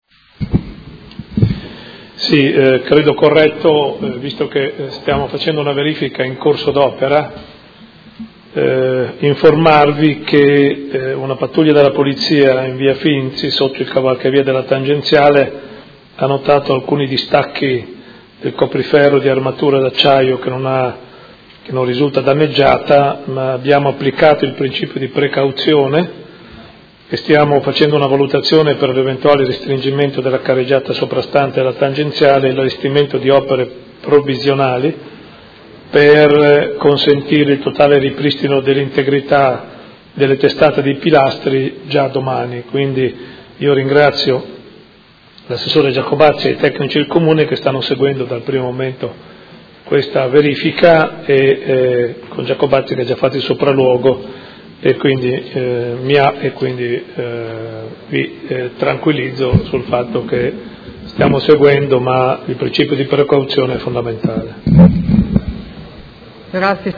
Seduta del 04/05/2017. Comunicazione del Sindaco su cavalcavia tangenziale Carducci